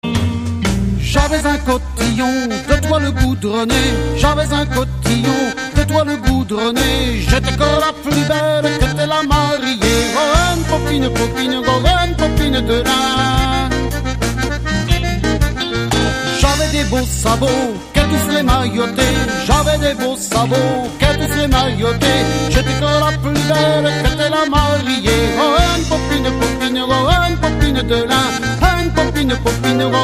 Musique : Traditionnel
Interprètes : Quatre Jean
Origine : Haute-Bretagne
Chant par les Quatre Jean sur l'album Que des mensonges en 2001 (Extrait Nozbreizh).